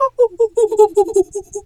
pgs/Assets/Audio/Animal_Impersonations/monkey_2_chatter_07.wav at master
monkey_2_chatter_07.wav